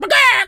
chicken_cluck_scream_long_03.wav